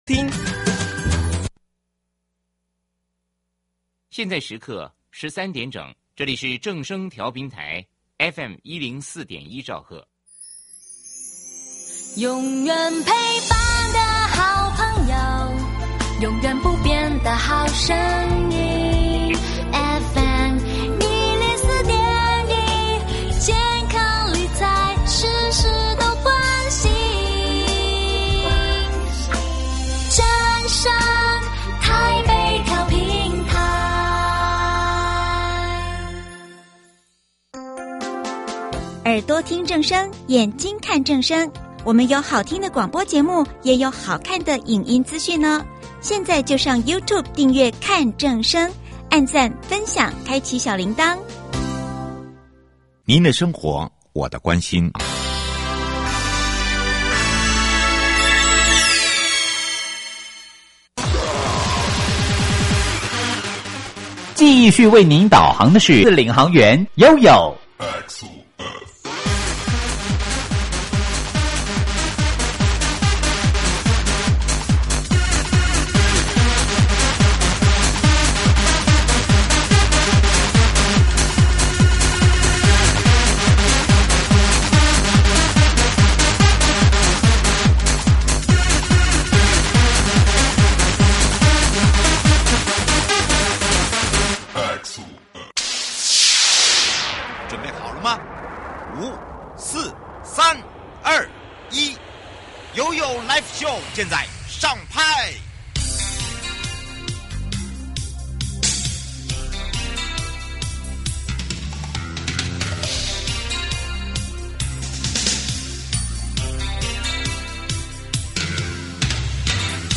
受訪者： 營建你我他 快樂平安行~七嘴八舌講清楚~樂活街道自在同行!(三) 主題：以人為本，打造友善安全的城市
節目內容： 國土署都市基礎工程組 台中市政府建設局陳大田局長(三)